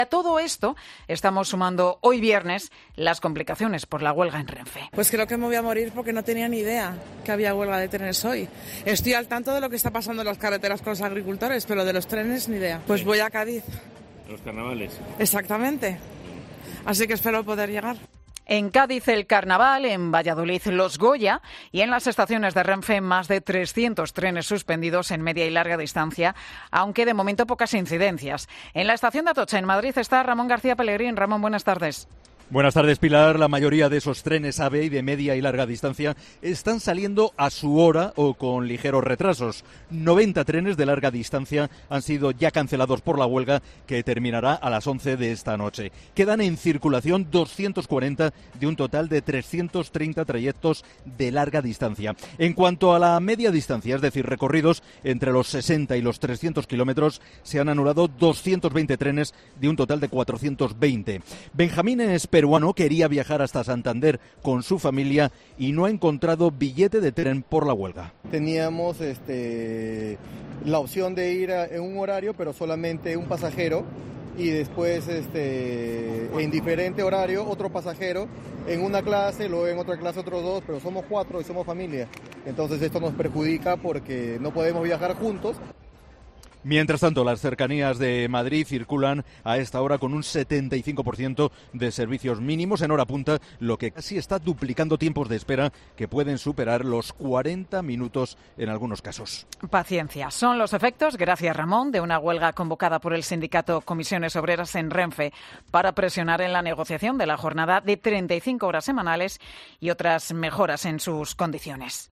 En COPE te contamos, desde la estación de tren de Atocha (Madrid), cómo se está desarrollando esta jornada de huelga